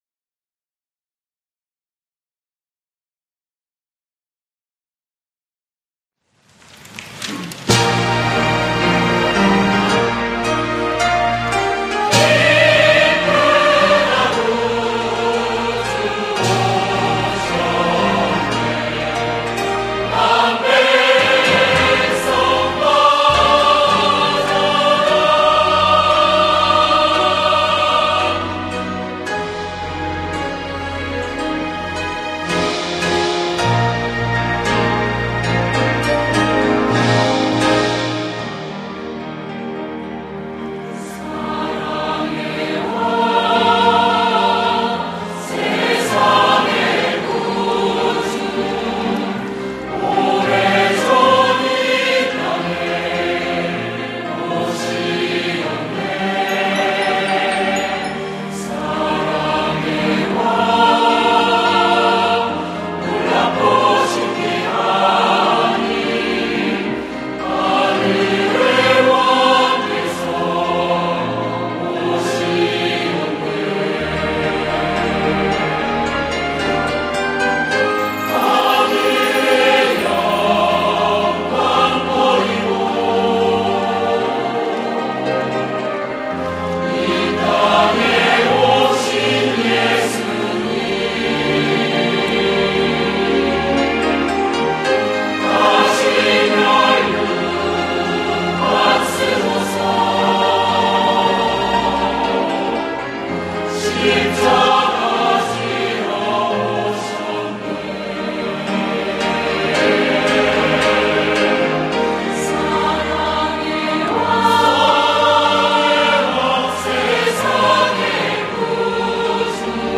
성탄절 칸타타